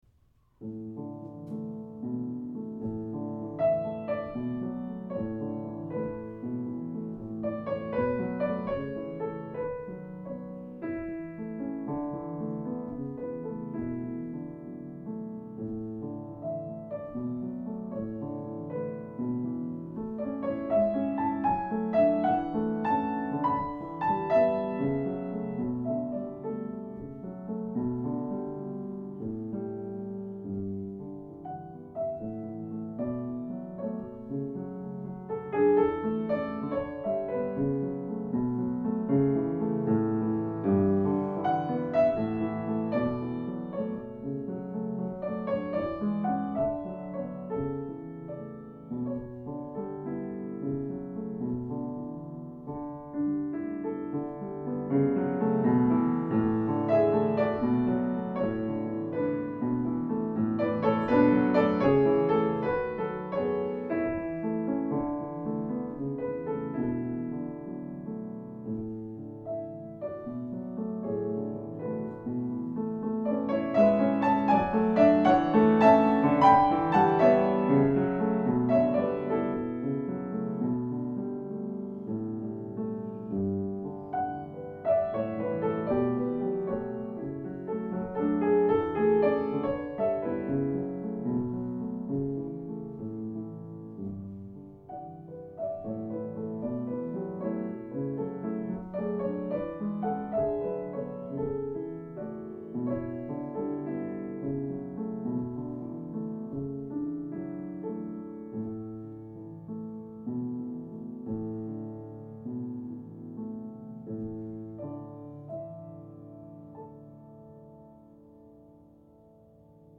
Piano Solo
Demo recording excludes encore.